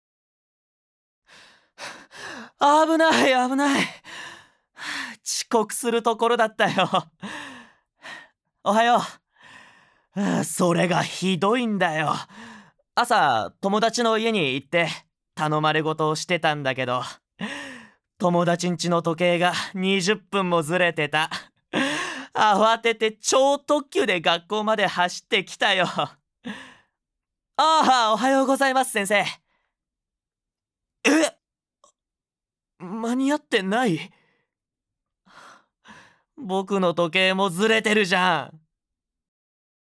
ボイスサンプル
●セリフ②20歳前後のノリの良い青年